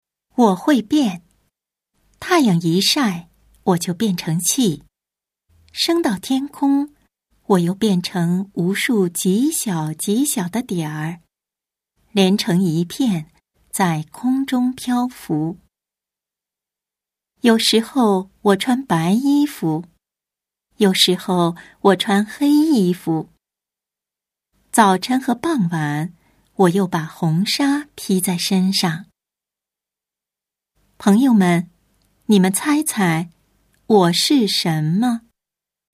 中国語ナレーター・ナレーション
北京語